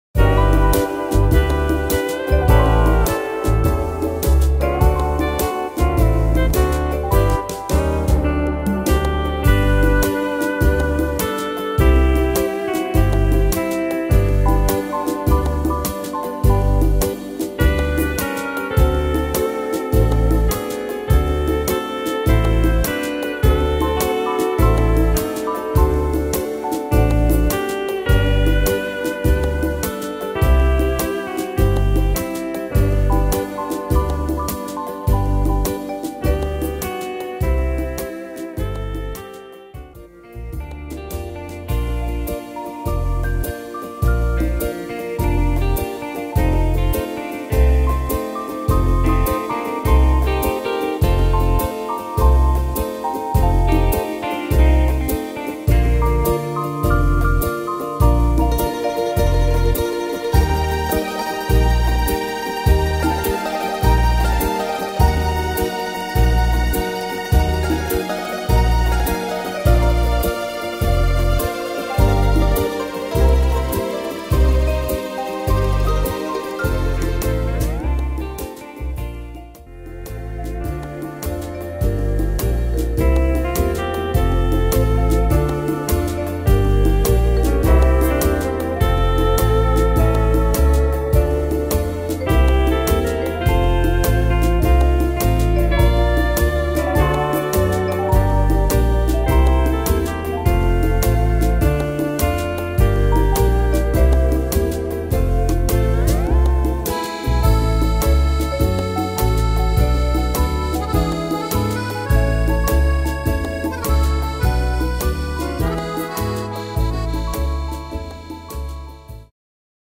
Tempo: 103 / Tonart: G-Dur